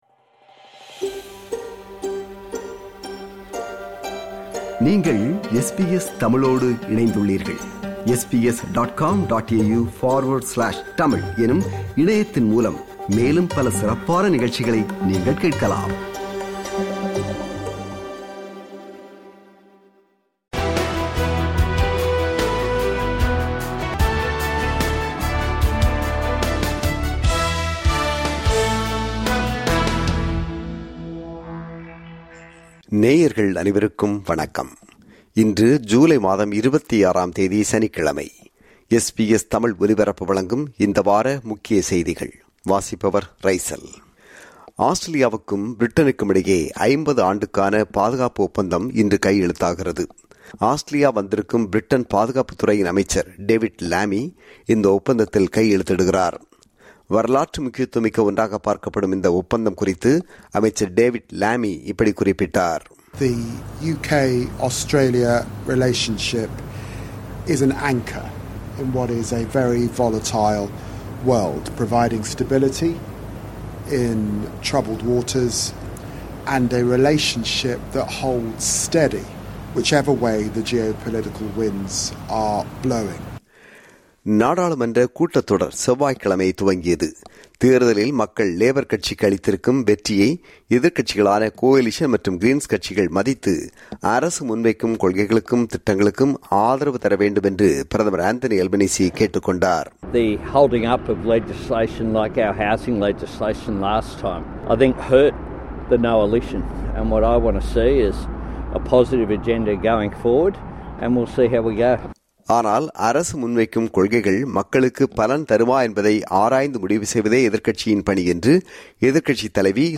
Weekly Highlights (20 July – 26 July 2025) To hear more podcasts from SBS Tamil, subscribe to our podcast collection.